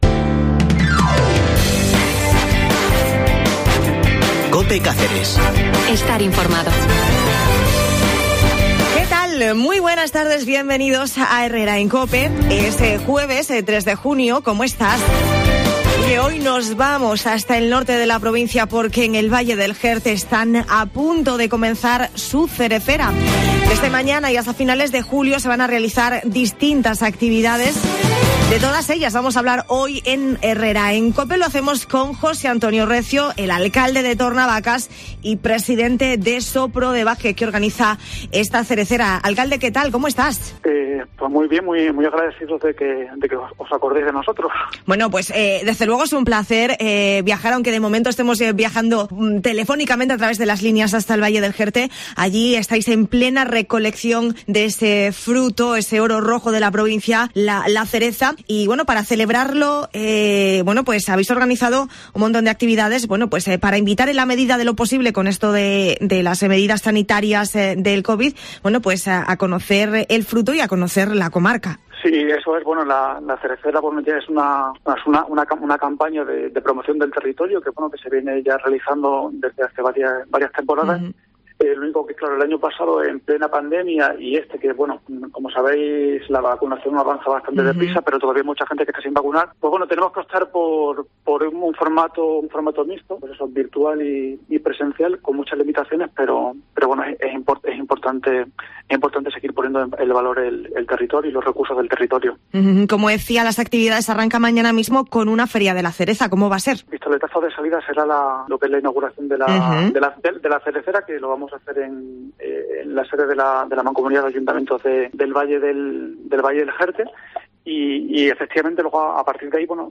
En Herrera en COPE Cáceres hablamos de todas estas actividades con José Antonio Recio, presidente de SOPRODEVAJE y acalde de Tornavacas, que organiza la Cerecera: